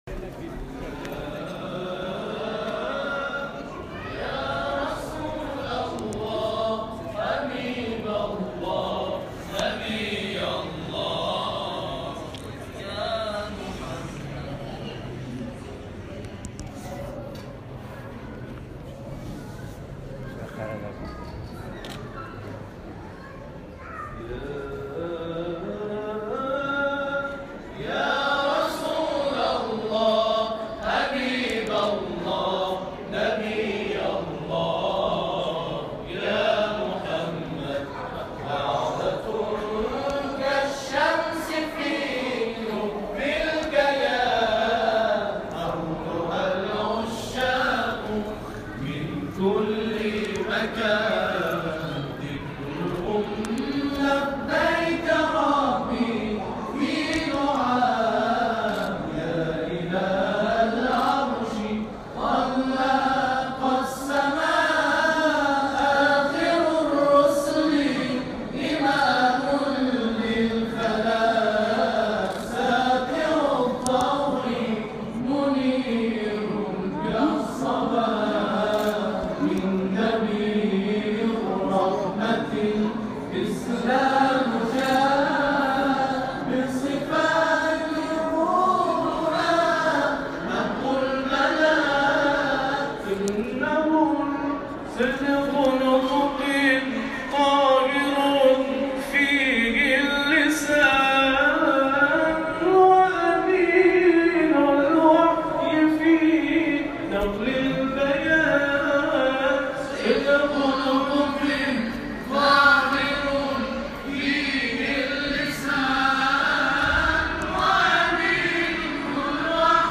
قاریان این کاروان در دفتر امام جمعه باغین، گلزار شهدای باغین و ... به تلاوت نور پرداختند.
گلزار شهدای باغین